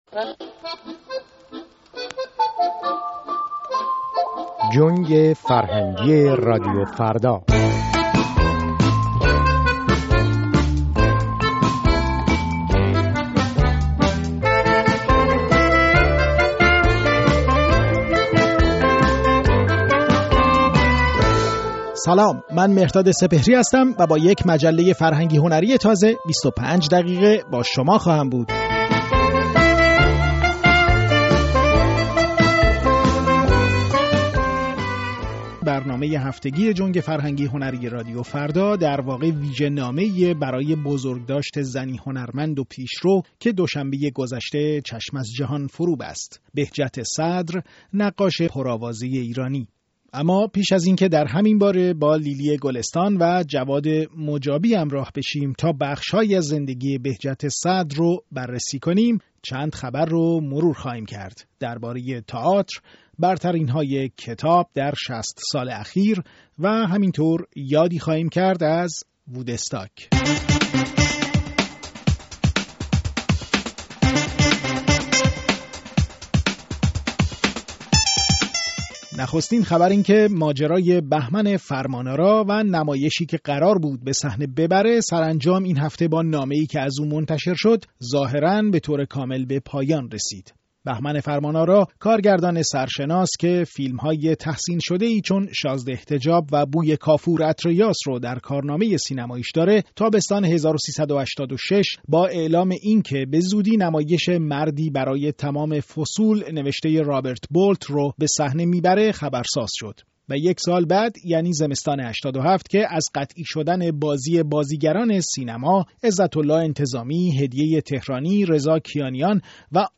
برنامه جنگ فرهنگی شامل مصاحبه با لیلی گلستان و جواد مجابی درباره کارنامه بهجت صدر هنرمند و نقاش